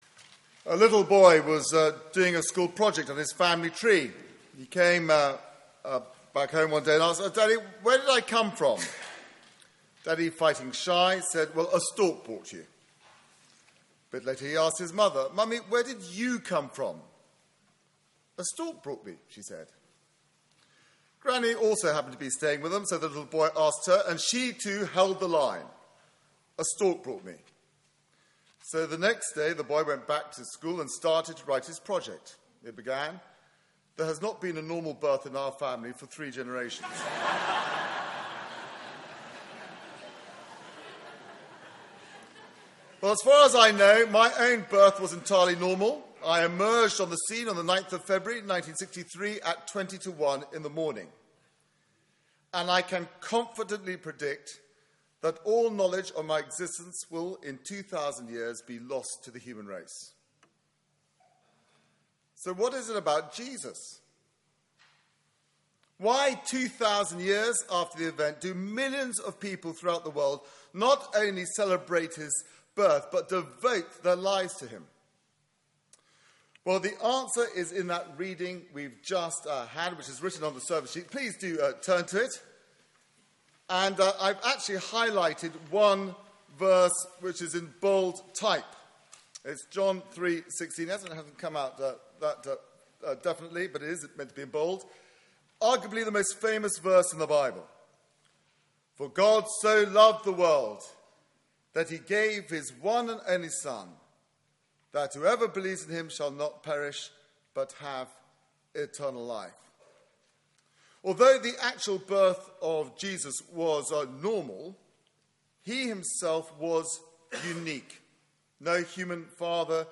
Media for 6:30pm Service on Sun 20th Dec 2015 19:00 Speaker
Theme: Carols By Candlelight Sermon